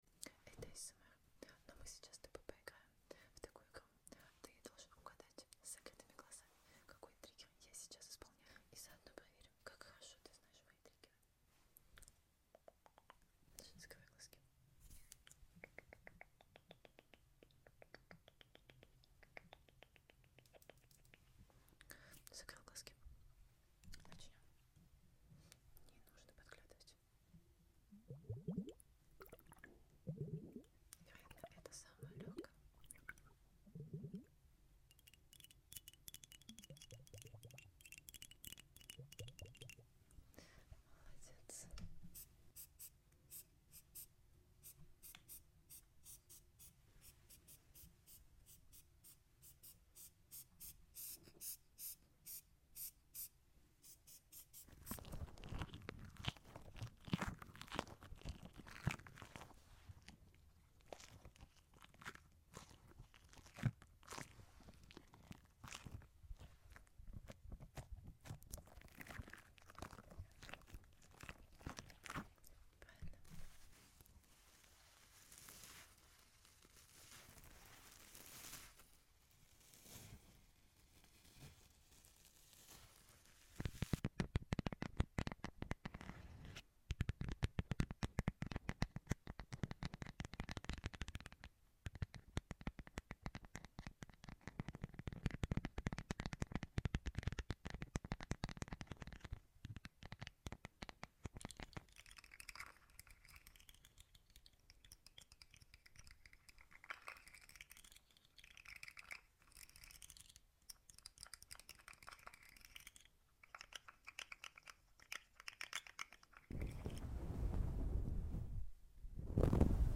Close your eyes and tell me what trigger I do, how many triggers did you guess?